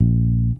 Bass 2_01.wav